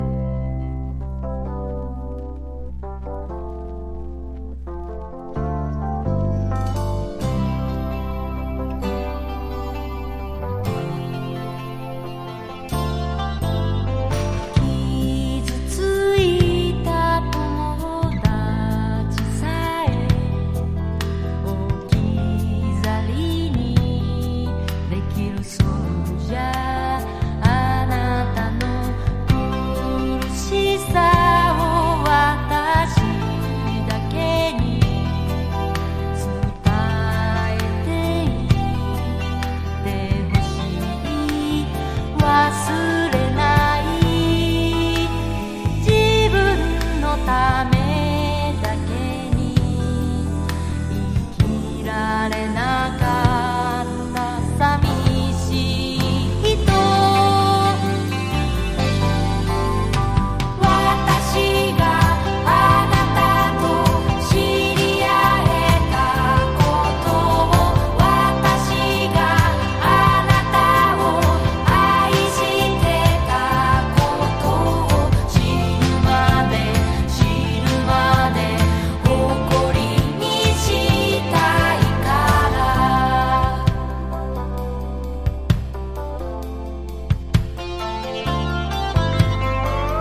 60-80’S ROCK# SSW / FOLK# 和モノ / ポピュラー